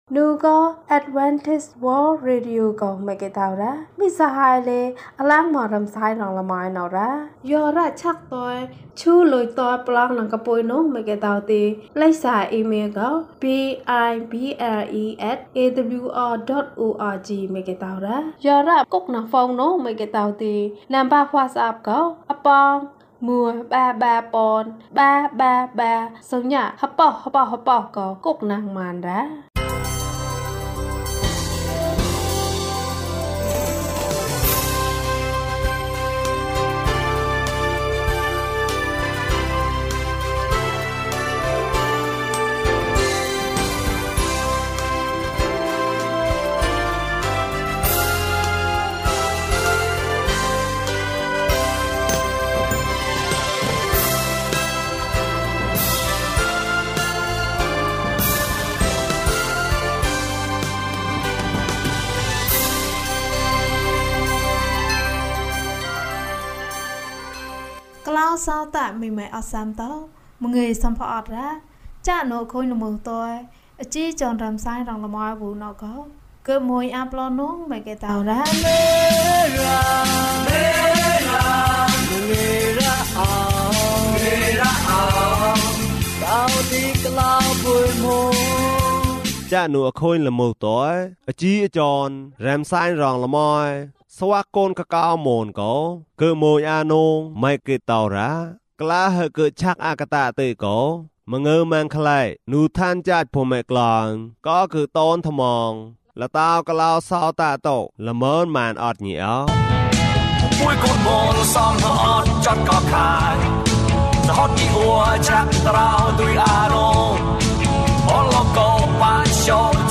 မင်းရဲ့ ကြင်နာမှုကို မျှဝေပါ။ အပိုင်း ၂ ကျန်းမာခြင်းအကြောင်းအရာ။ ဓမ္မသီချင်း။ တရားဒေသနာ။